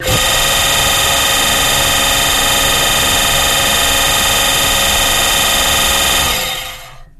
Boat Thrust
Jet Boat Water Pump, Thrust Trimmer